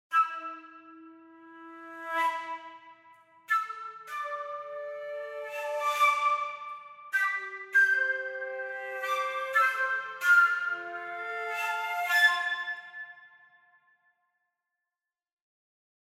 Deutlich umfangreicher ist das Angebot an Effekten bei den Flöten:
Hier hat sich ein Nebengeräusch eingeschlichen, das nicht zum Instrument gehört – eine Ausnahme in der ansonsten sehr sauber aufgezeichneten Library.